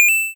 pickup.ogg